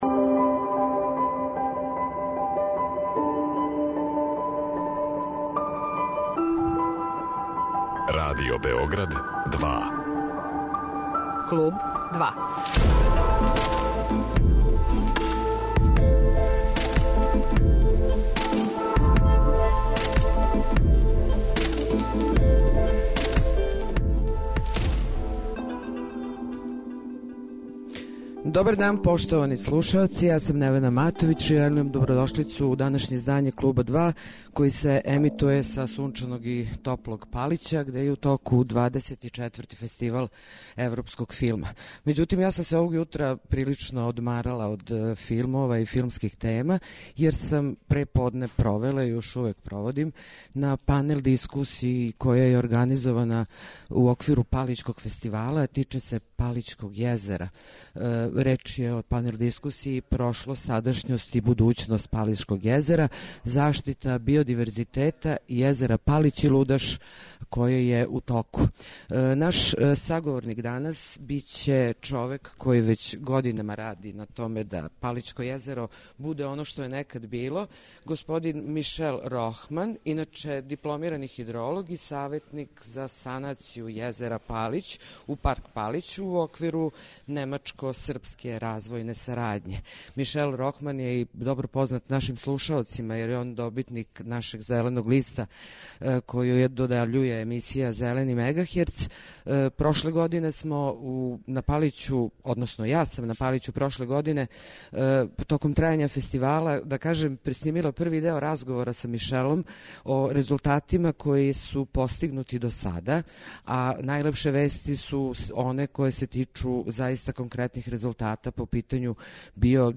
Уживо са Палића
Данашње издање "зеленог и еколошког" Клуба 2 емитује се уживо са Палића, где је у току 24. Фестивал европског филма.